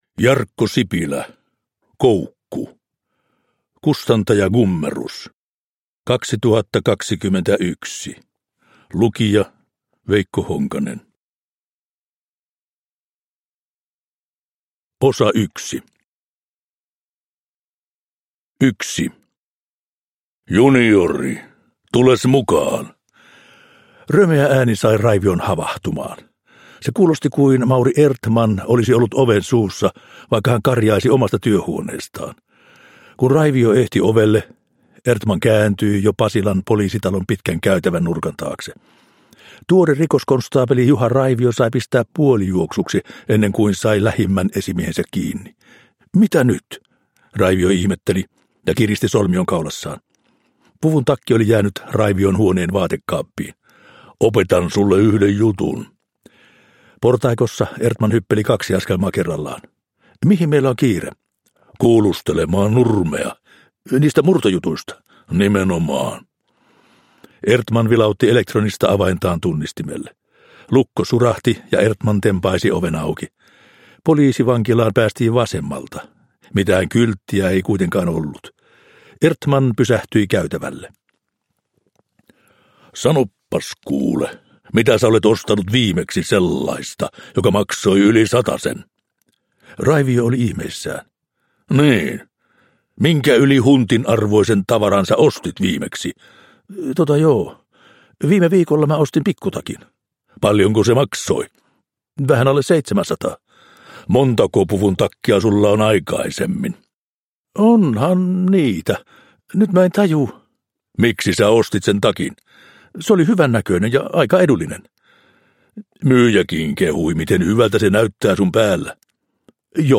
Koukku (ljudbok) av Jarkko Sipilä